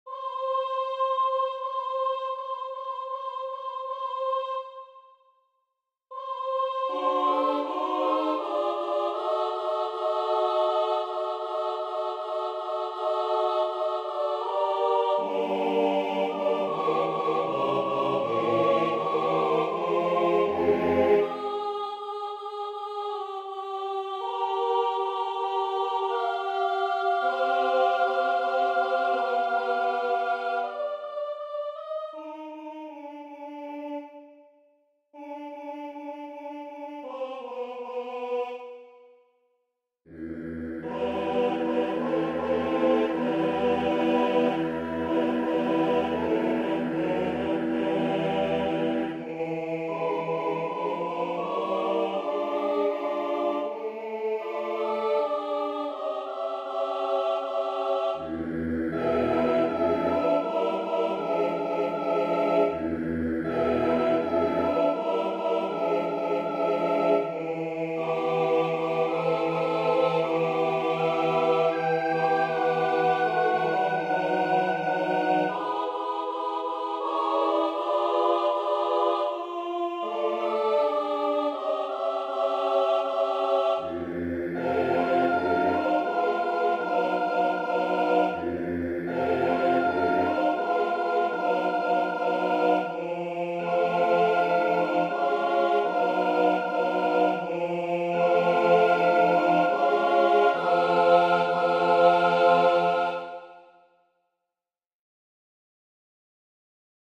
Number of voices: 4vv Voicing: SATB Genre: Sacred, Motet
Language: Latin Instruments: A cappella
Score information: A4, 5 pages, 157 kB Copyright: Personal Edition notes: The MP3 file is an electronic realisation of the music which is slightly better than the MIDI.